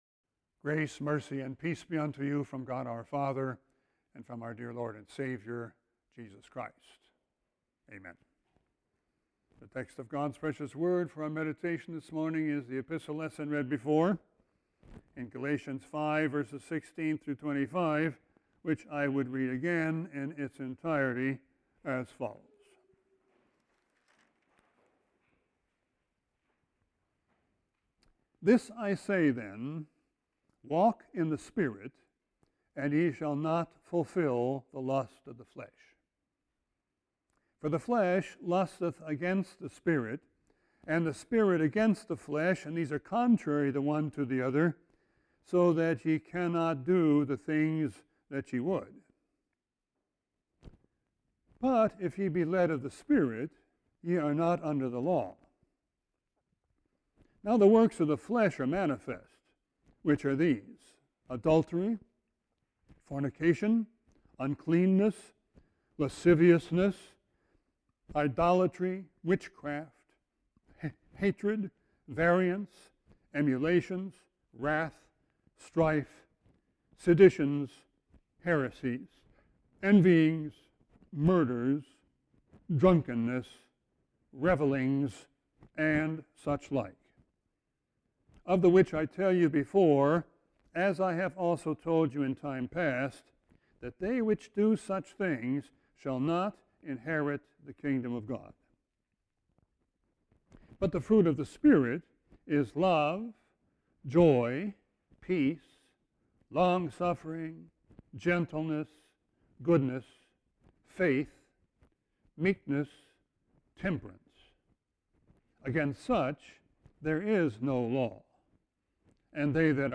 Sermon 9-13-20.mp3